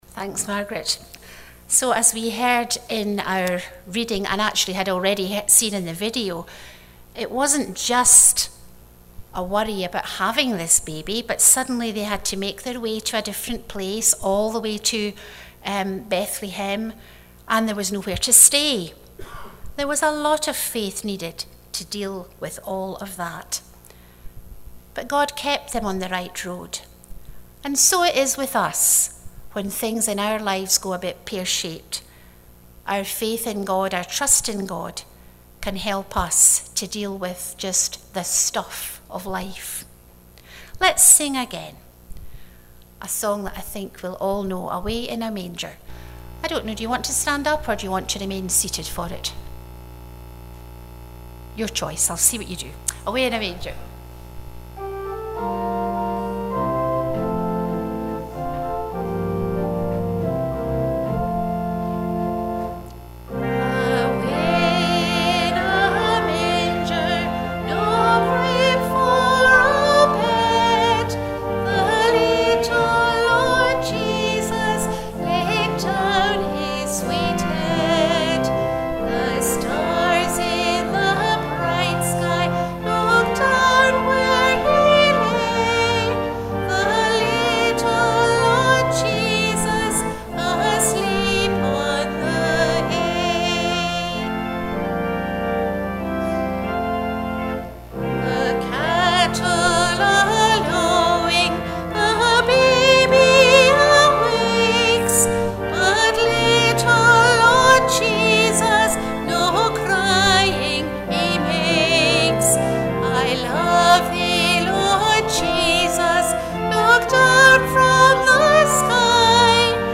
Christmas Eve Afternoon Family Service